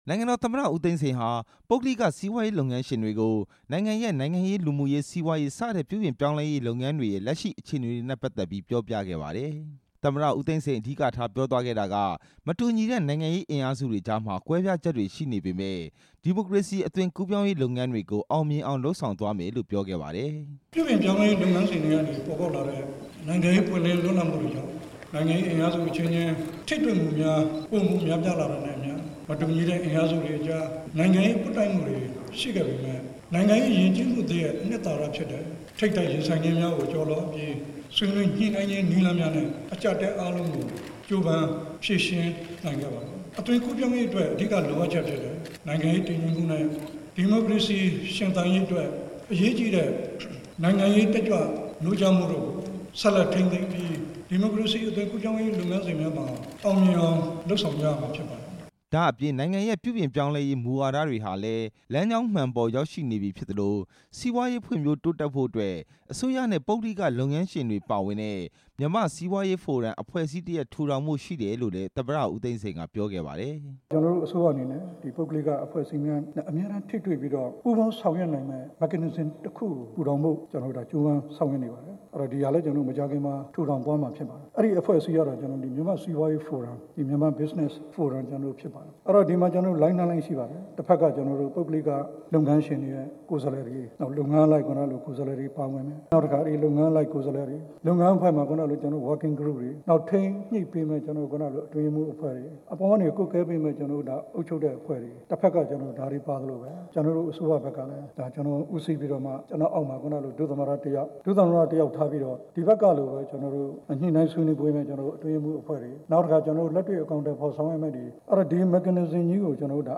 တွေ့ဆုံပွဲအကြောင်း တင်ပြချက်